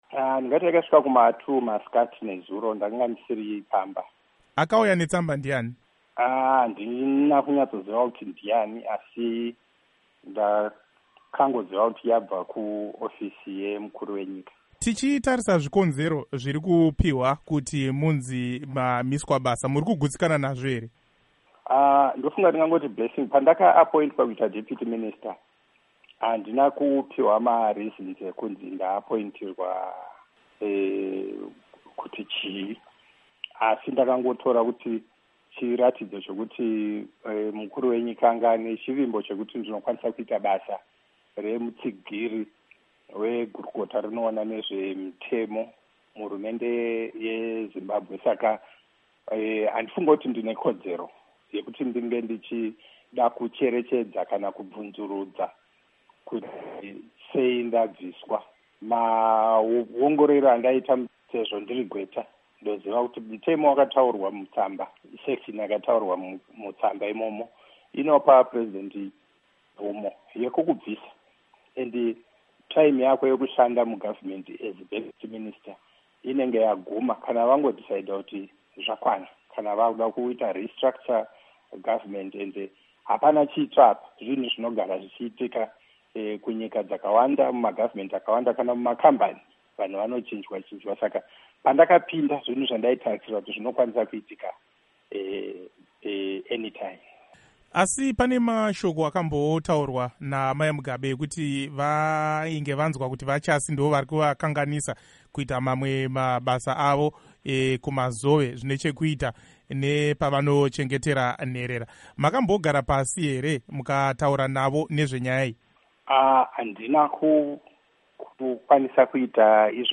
Hurukuro naVaFortune Chasi